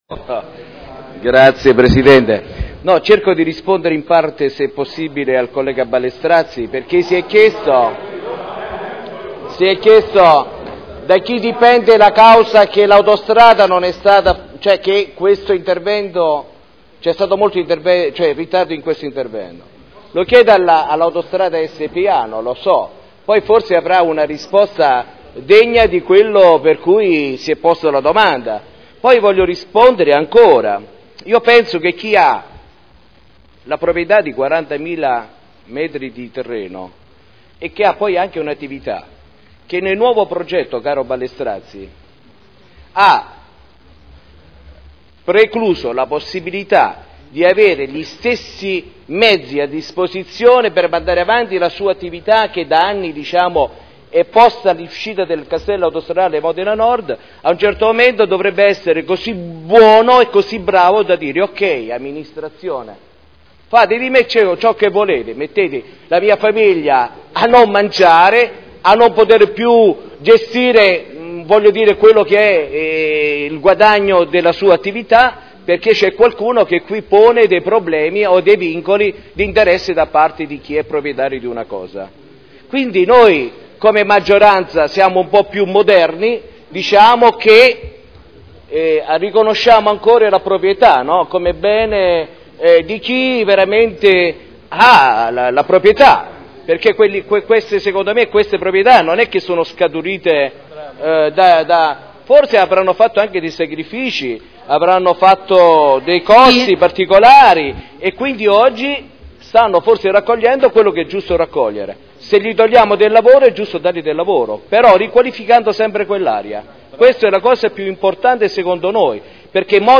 Maurizio Dori — Sito Audio Consiglio Comunale
Dichiarazioni di voto